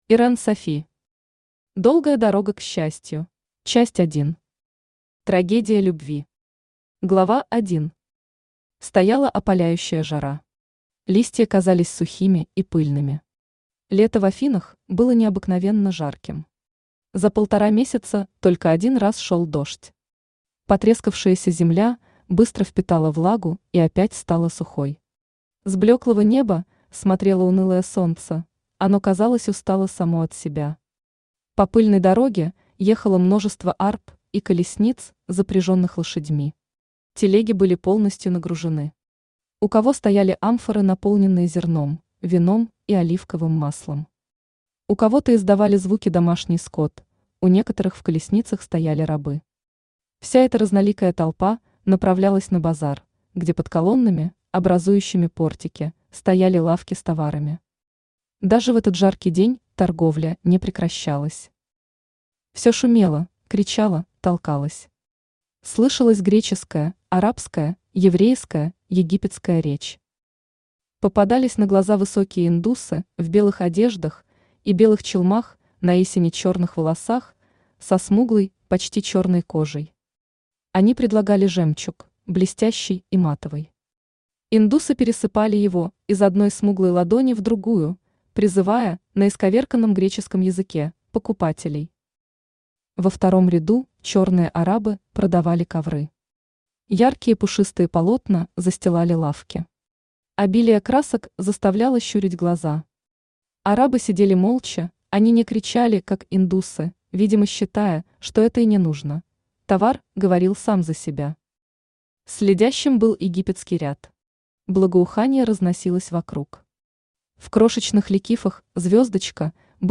Аудиокнига Долгая дорога к счастью | Библиотека аудиокниг
Aудиокнига Долгая дорога к счастью Автор Ирен Софи Читает аудиокнигу Авточтец ЛитРес.